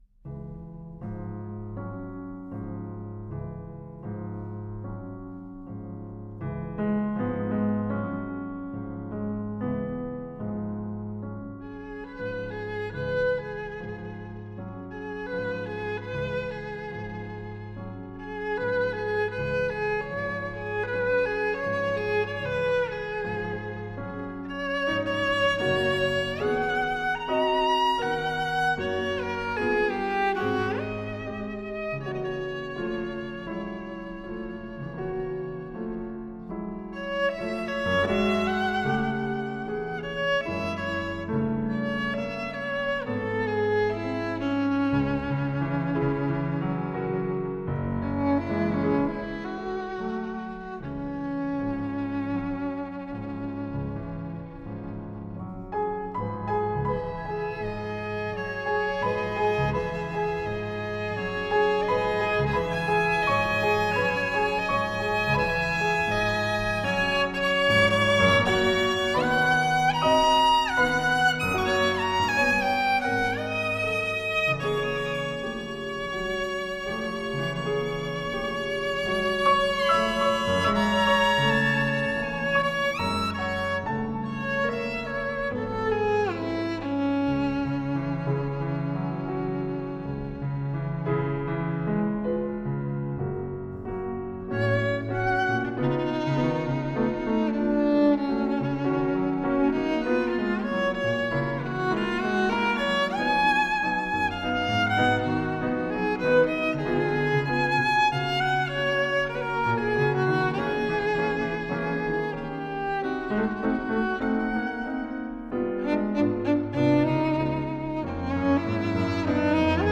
klavír